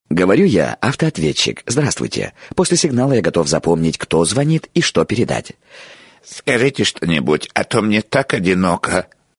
Прикольный автоответчик - Одинокий АОН